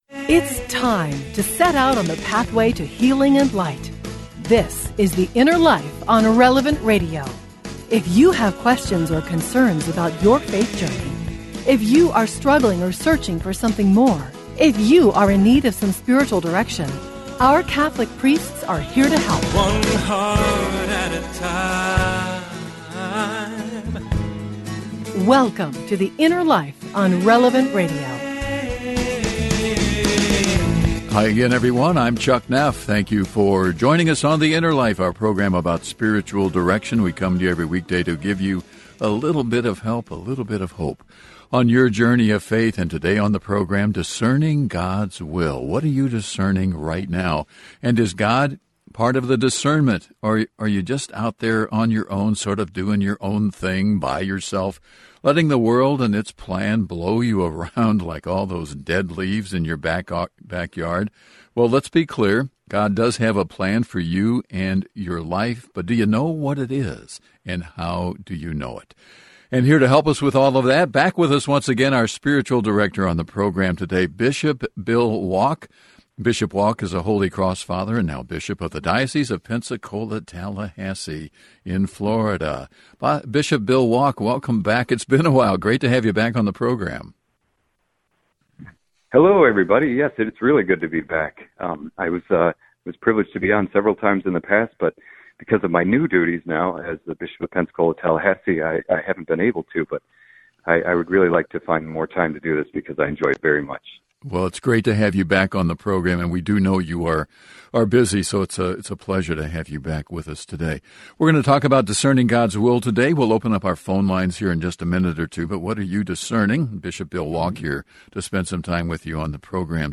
Bishop Bill Wack, of the Diocese of Pensacola-Tallahassee, stopped by The Inner Life™ recently to share about discernment, and what principles we can use when trying to seek the will of God in our lives.